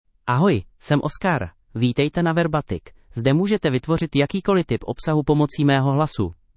Oscar — Male Czech (Czech Republic) AI Voice | TTS, Voice Cloning & Video | Verbatik AI
OscarMale Czech AI voice
Oscar is a male AI voice for Czech (Czech Republic).
Voice sample
Listen to Oscar's male Czech voice.
Male